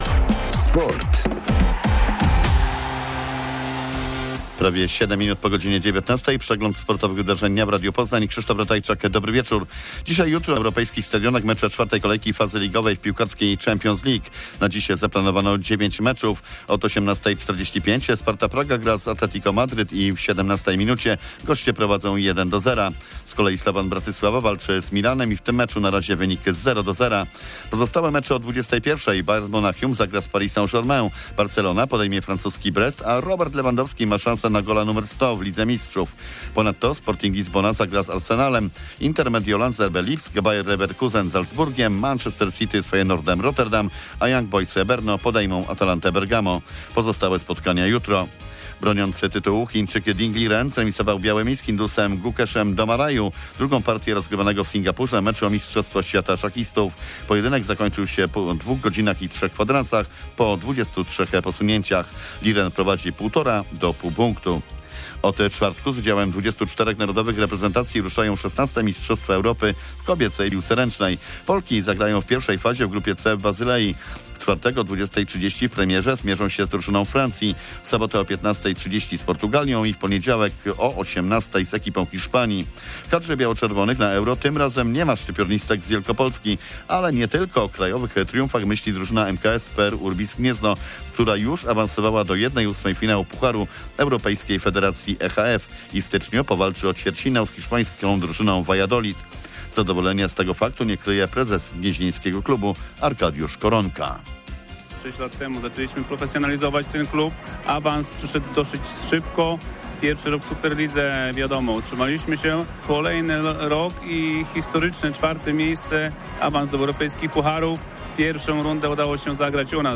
26.11.2024 SERWIS SPORTOWY GODZ. 19:05